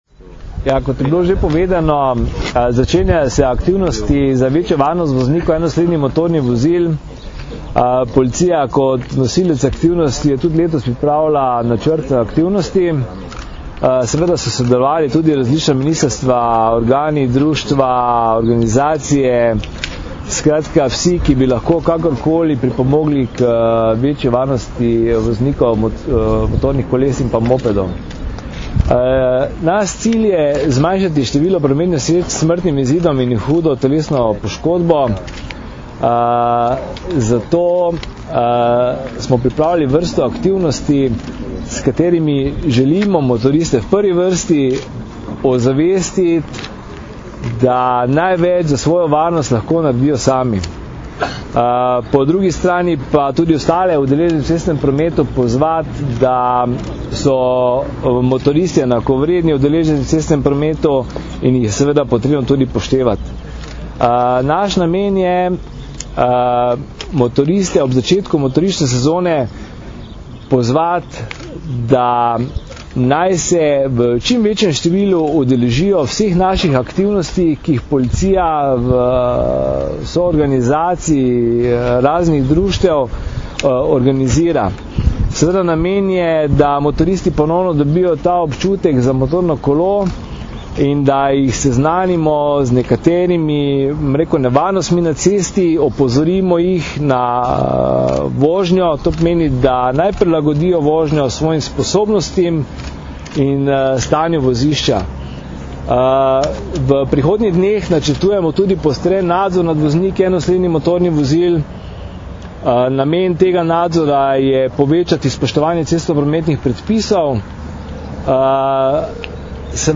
Naj bo motoristična sezona prijetna in varna - informacija z novinarske konference
Zvočni posnetek izjave